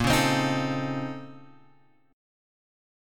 A#mM11 chord {6 4 x 5 4 5} chord